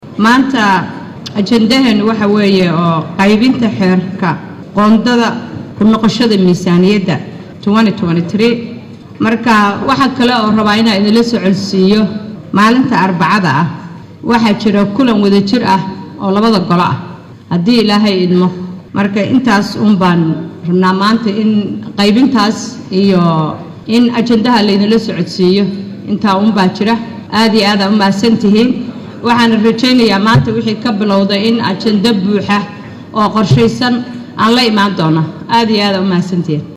Guddoomiyaha ku xigeenka koowaad ee golaha shacabka Soomaaliya Marwo Sacdiya Yaasiin Xaaji ayaa wargelintan ku dhawaaqday.